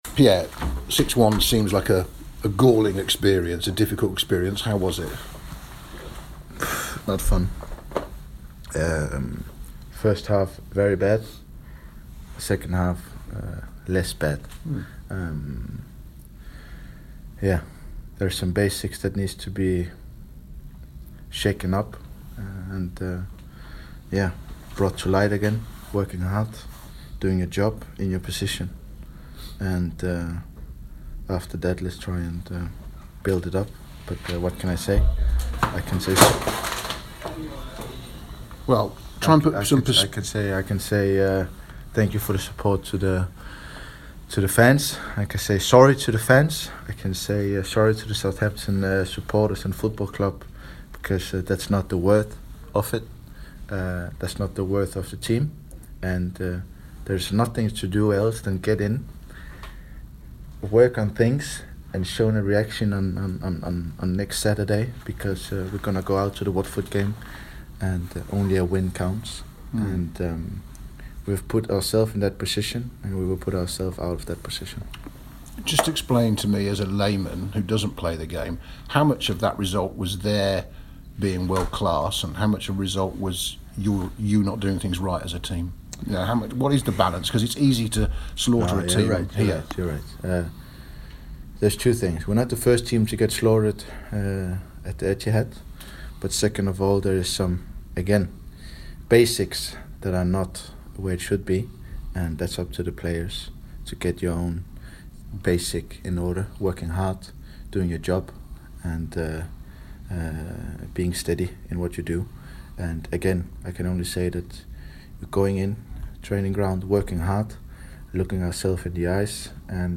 Pierre-Emile Højbjerg speaks after the 6-1 defeat at Man City